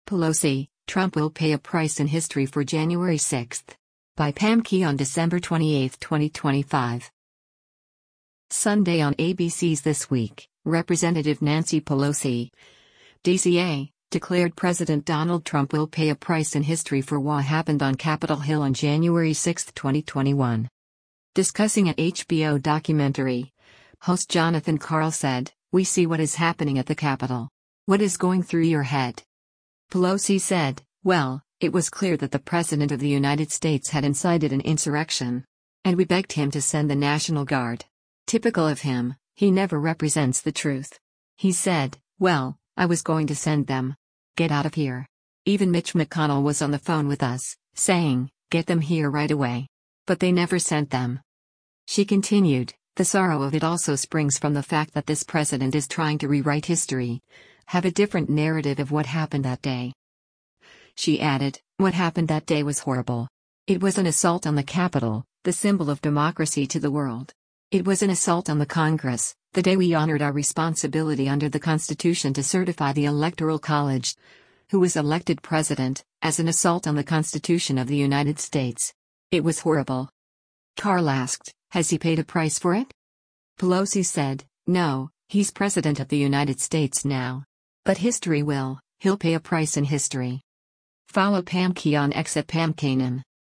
Sunday on ABC’s “This Week,” Rep. Nancy Pelosi (D-CA) declared President Donald Trump will “pay a price in history” for wha happened on Capitol Hill on January 6, 2021.